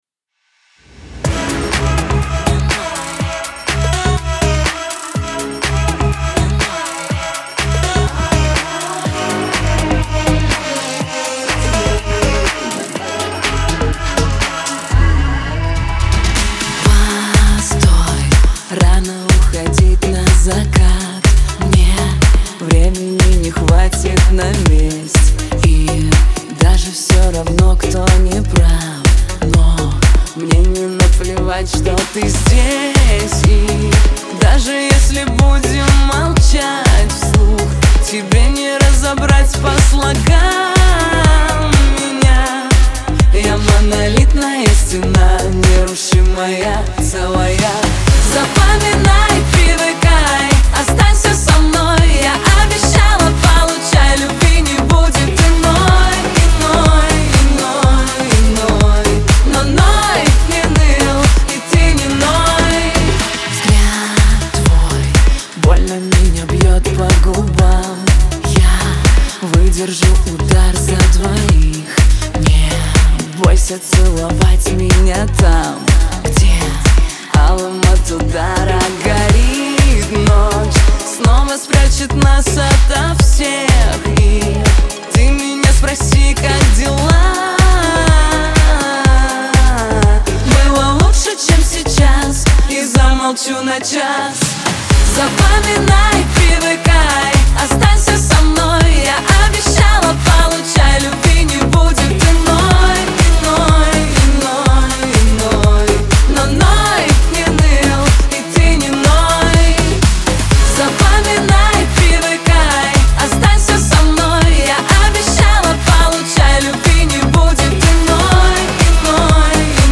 дуэт
эстрада
танцевальная музыка